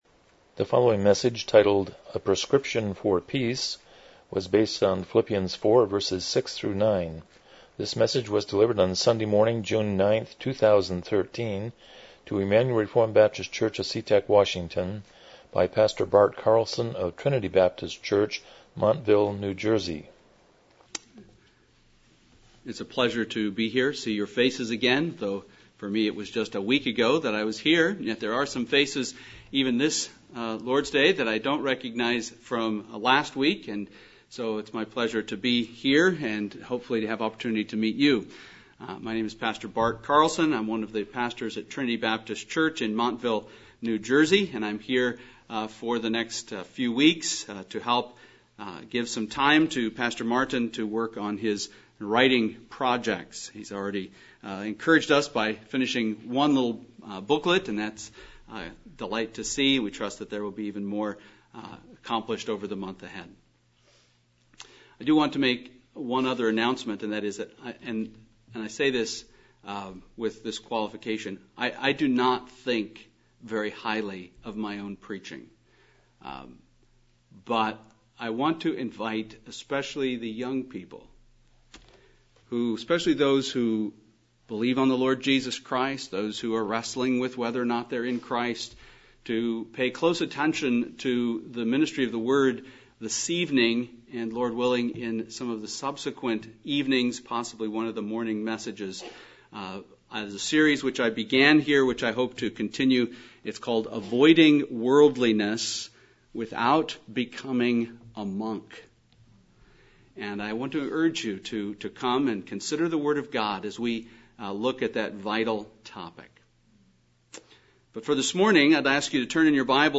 Philippians 4:6-9 Service Type: Morning Worship « Evangelism #1 2 Avoiding Worldliness